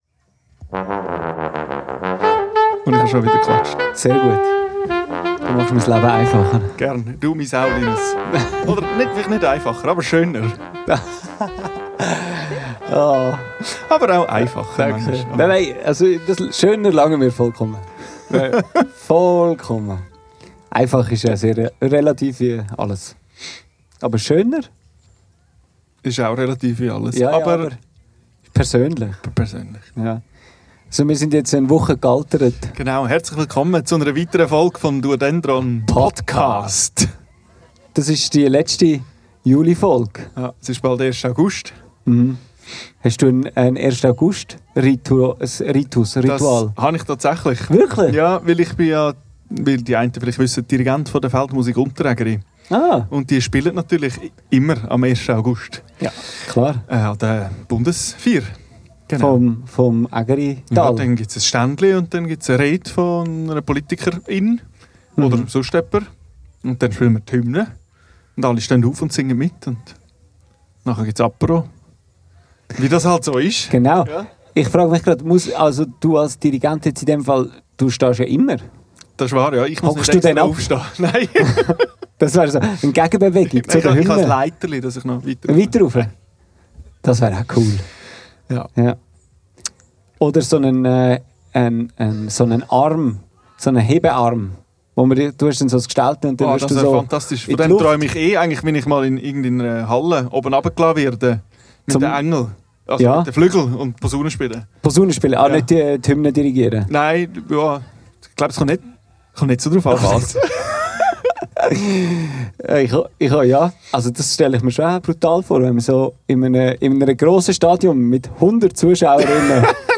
Und schon sind wir bei der letzten Folge im Juli und somit bei der vorerst letzten Live-Podcast-Folge angekommen.
Aufgenommen am 27.06.25 im Seeclub Hünenberg.